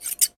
Tijera recortando un papel 1
Sonidos: Acciones humanas Sonidos: Oficina